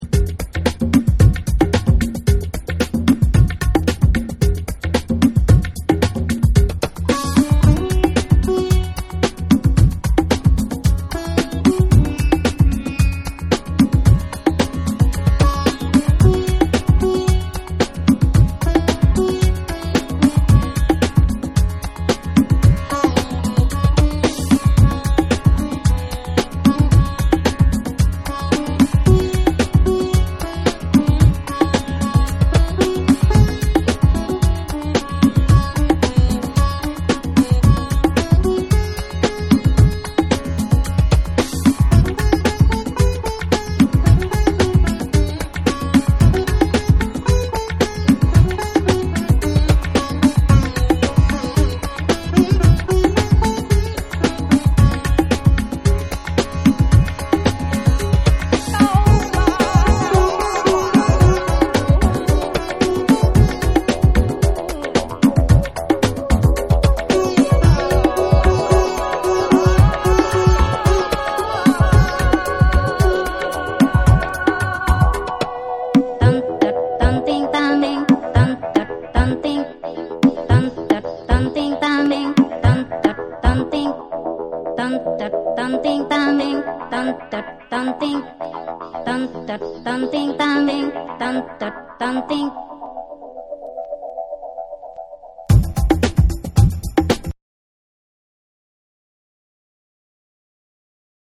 ブーガルー・フレイヴァーのソウルフルでファンキーなラテン・ブレイクを披露した1（SAMPLE1）。
プチノイズ入る箇所あり。
BREAKBEATS / ORGANIC GROOVE